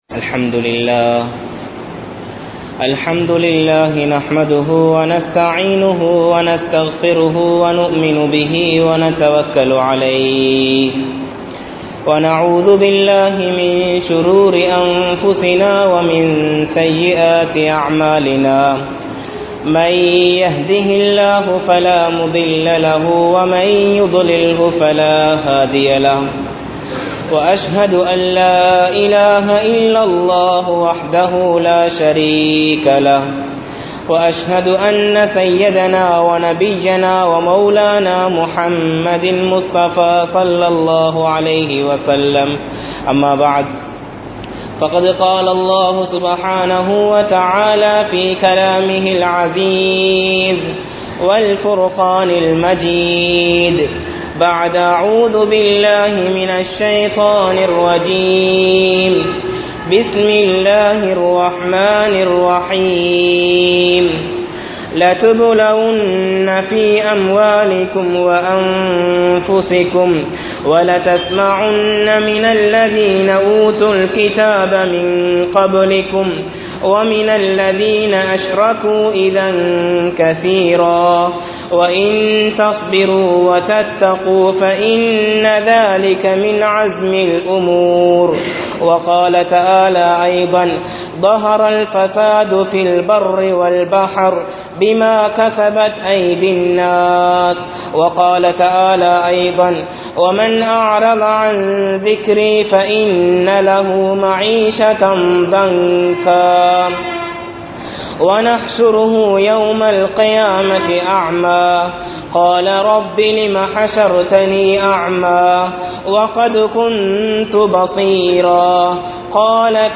Nerukkadiyaana Nilamaikku Kaaranam Yaar? (நெருக்கடியான நிலமைக்கு காரணம் யார்?) | Audio Bayans | All Ceylon Muslim Youth Community | Addalaichenai